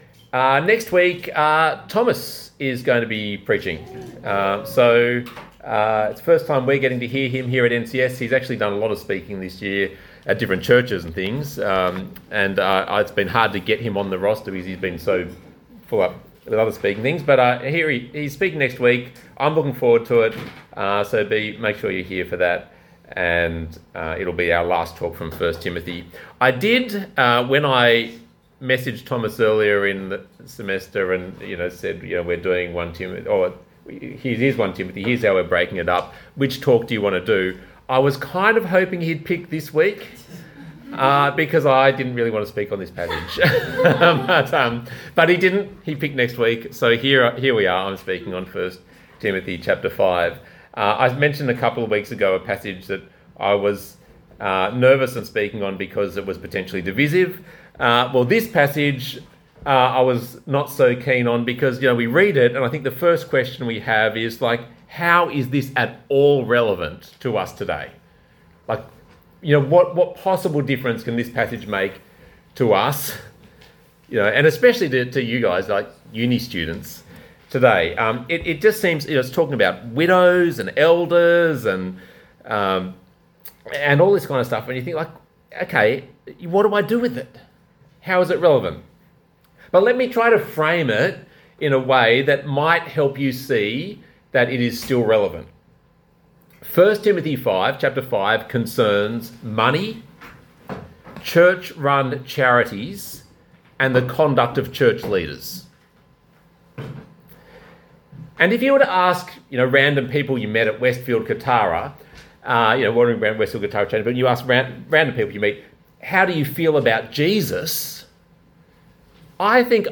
Talk Type: Bible Talk Topics: church , elders , leadership , widows